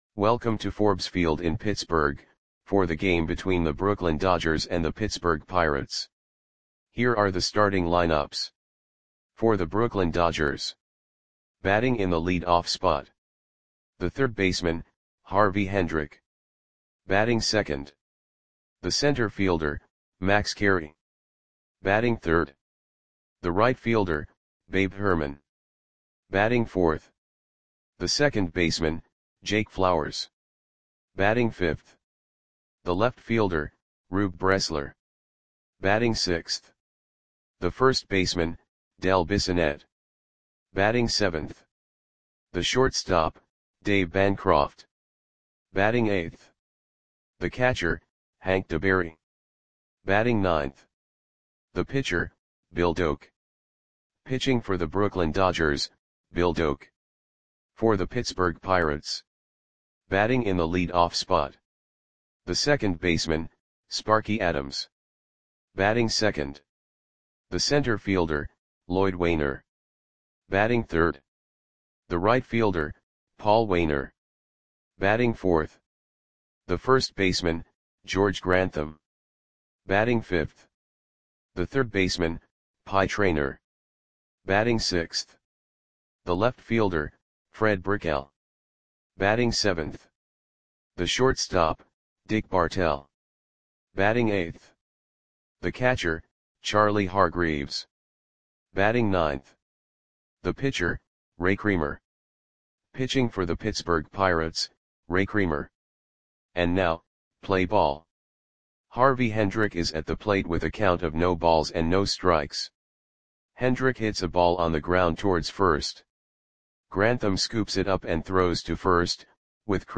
Audio Play-by-Play for Pittsburgh Pirates on August 13, 1928
Click the button below to listen to the audio play-by-play.